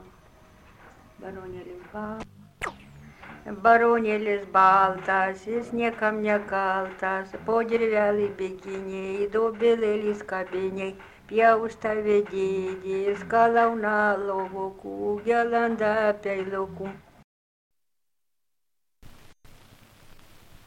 Dalykas, tema daina
Erdvinė aprėptis Dargužiai Mečiūnai
Atlikimo pubūdis vokalinis